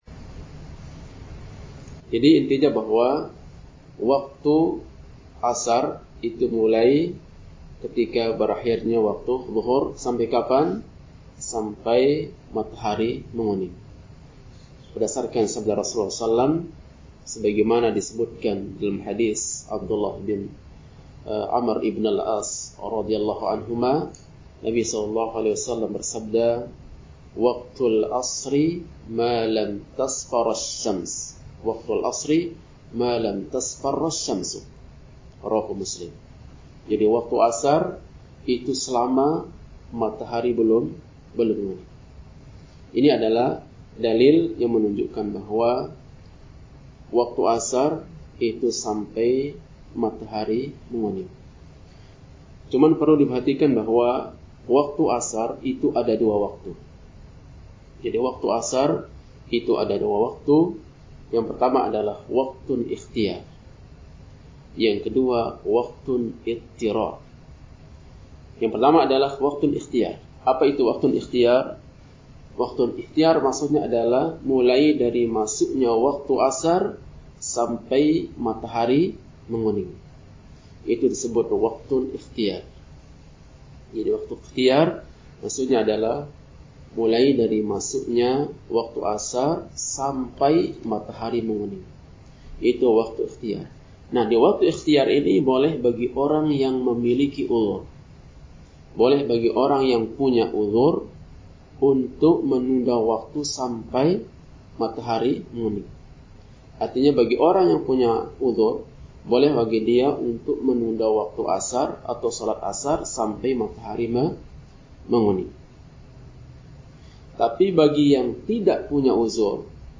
Kajian Ahad – Doha Membahas